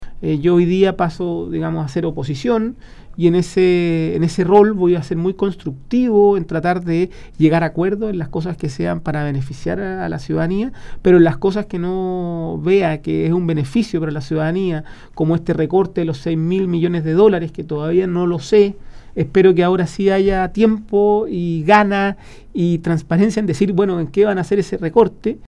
En ese sentido y tras el doloroso resultado, el diputado electo por la provincia de Cautín, José Montalva, señaló que ahora serán oposición y dijo que intentará trabajar en acuerdos que sean de beneficio para la ciudadanía.